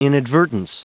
Prononciation du mot inadvertence en anglais (fichier audio)